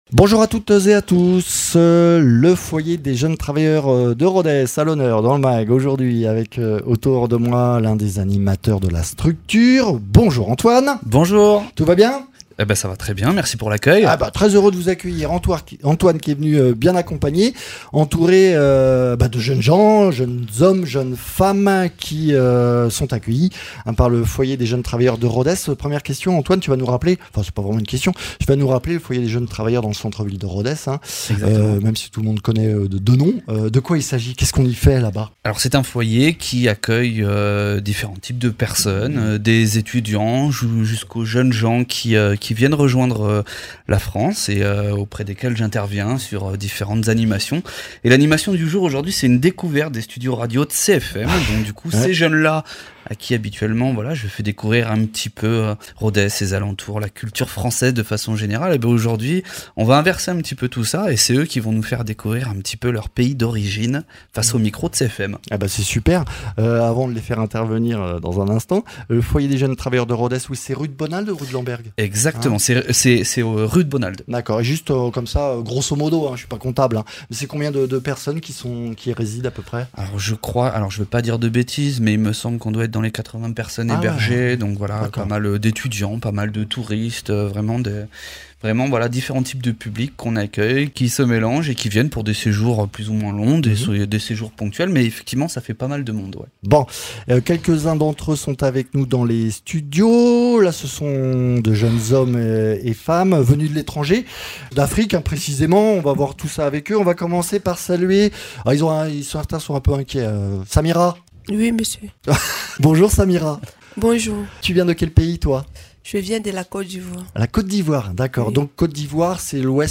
Rencontre avec plusieurs jeunes africains qui vivent au foyer des jeunes travailleurs de Rodez et qui découvrent la France et l’Aveyron depuis quelques mois
Interviews